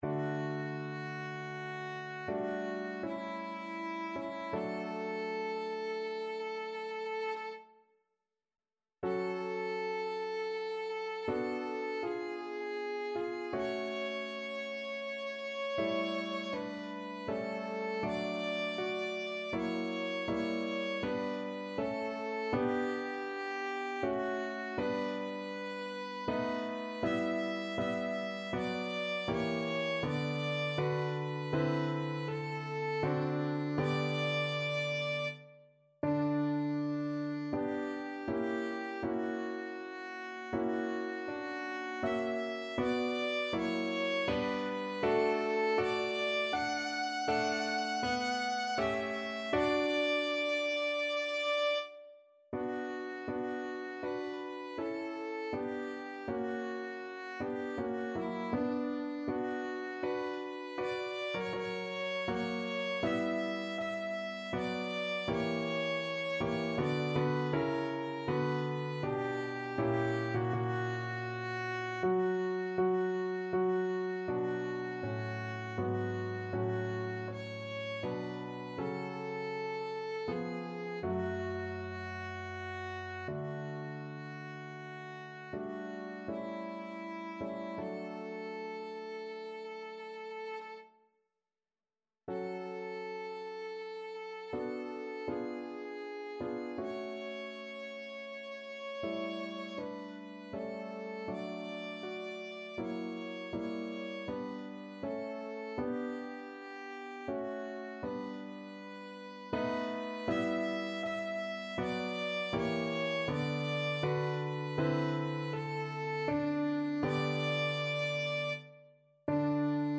Violin
~ = 80 Andante ma non lento
3/4 (View more 3/4 Music)
D5-G6
D major (Sounding Pitch) (View more D major Music for Violin )
Classical (View more Classical Violin Music)